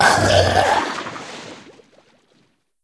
c_seasnake_hit3.wav